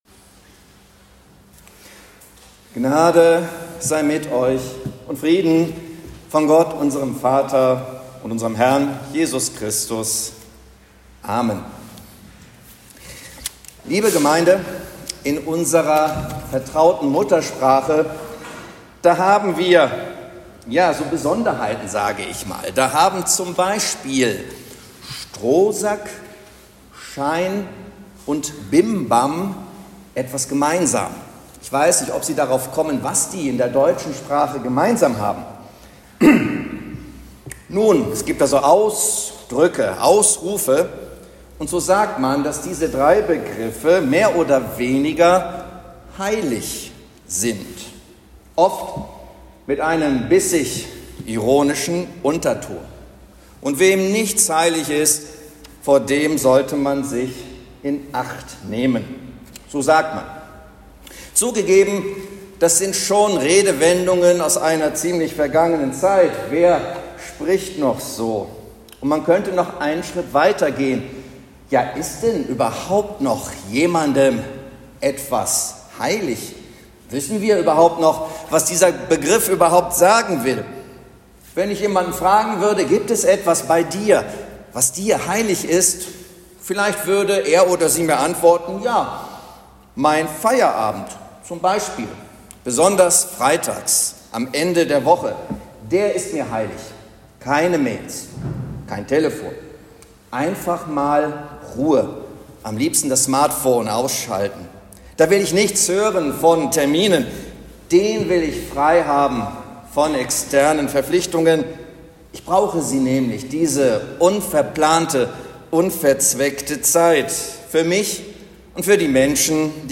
Predigt zu Invocavit